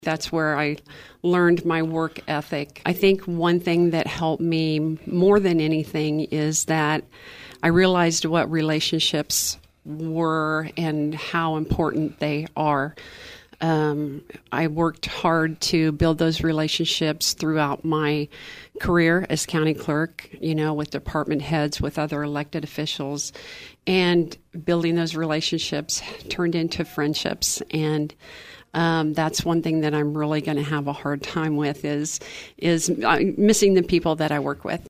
During an interview with KVOE on Monday’s Talk of Emporia, Vopat reflected on her 16-year career which began in 2008 when she was elected to replace longtime clerk Karen Hartenbower.